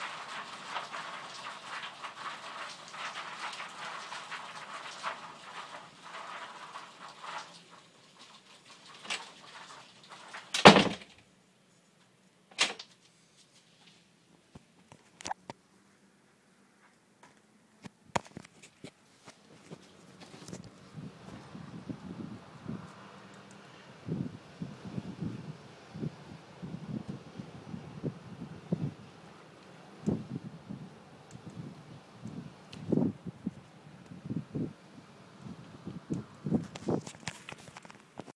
wind – Page 9 – Hofstra Drama 20 – Sound for the Theatre
Location: Outside Lowe early in the morning
Sounds Heard: Birds chirping, The trees doing that thing that they do with the breezes.